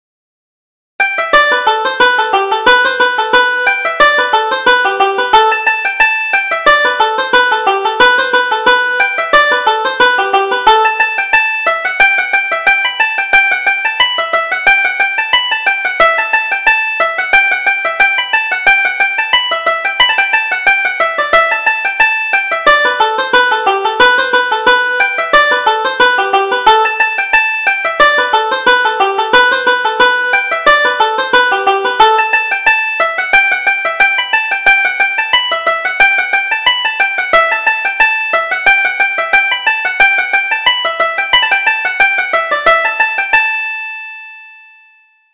Reel: The Blackberry Blossom